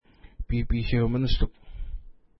Pronunciation: pi:pi:tʃew ministuk
Pronunciation